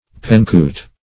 penchute - definition of penchute - synonyms, pronunciation, spelling from Free Dictionary